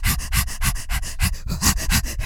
HYPERVENT 3.wav